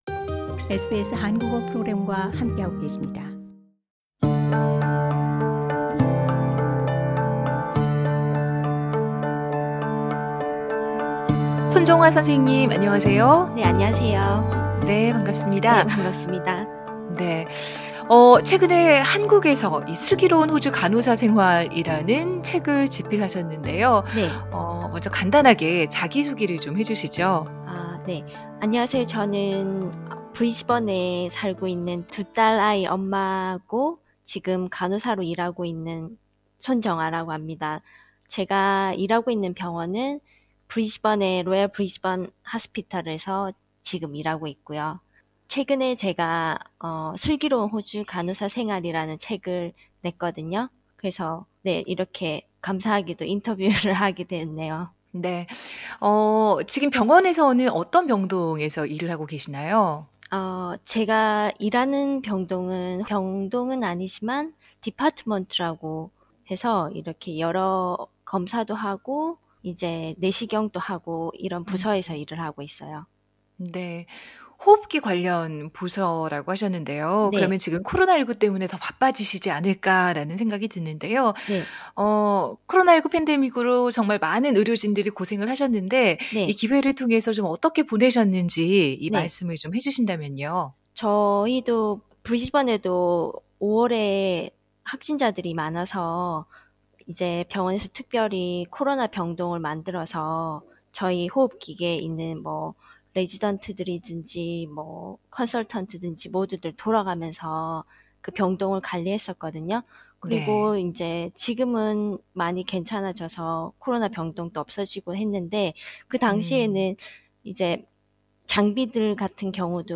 Queensland Health supplied The full interview is available on the podcast above.